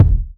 • Sharp Urban Bass Drum Single Shot C# Key 78.wav
Royality free bass drum sample tuned to the C# note. Loudest frequency: 110Hz
sharp-urban-bass-drum-single-shot-c-sharp-key-78-zl1.wav